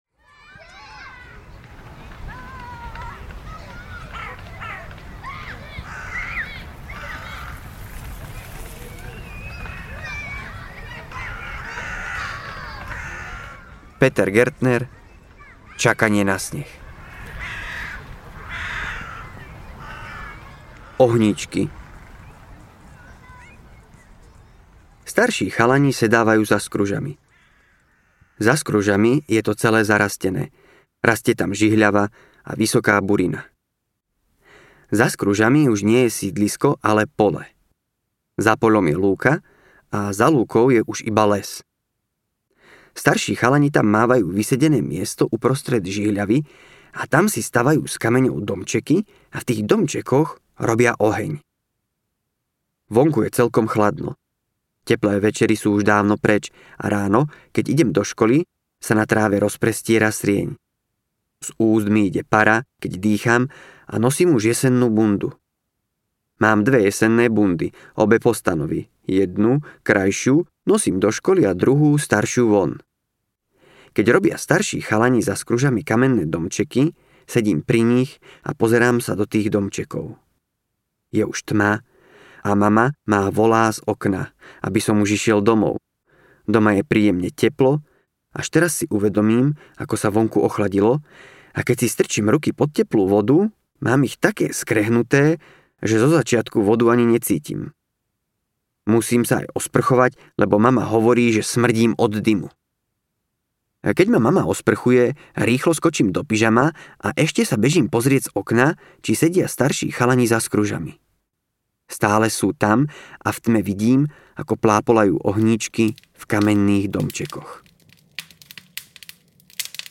Čakanie na sneh audiokniha
Ukázka z knihy